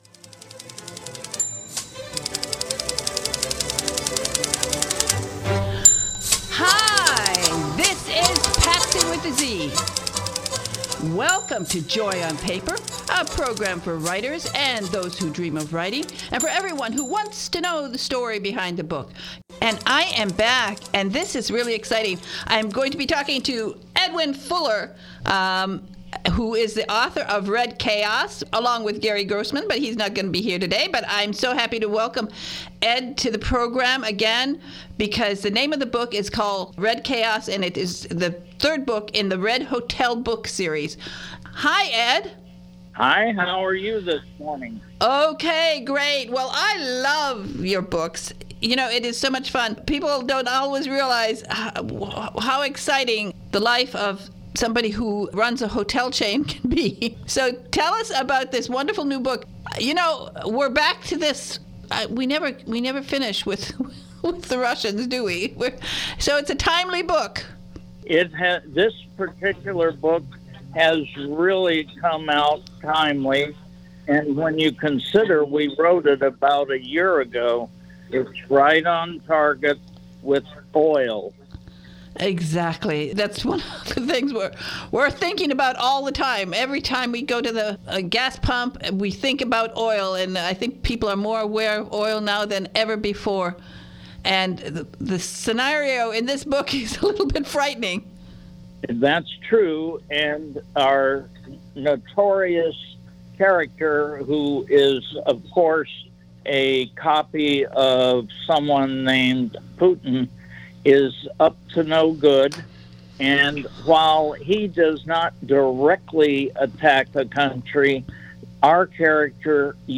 This is the first interview today